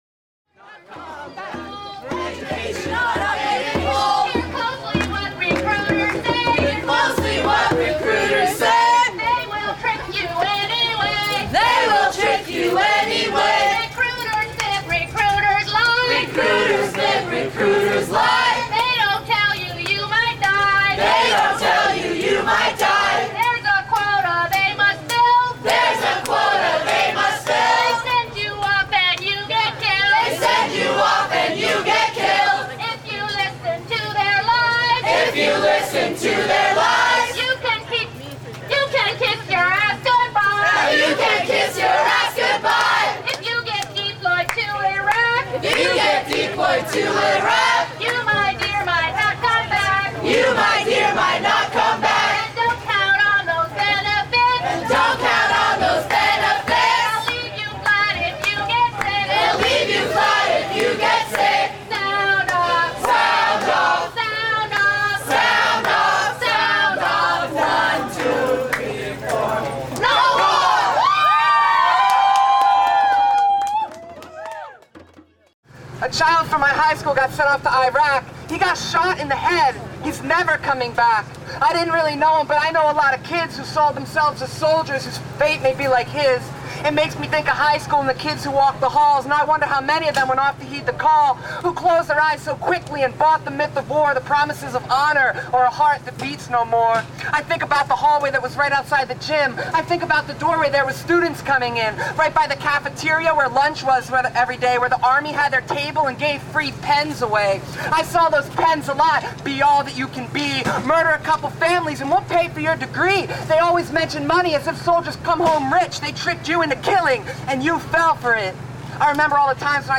College Not Combat Rally : Indybay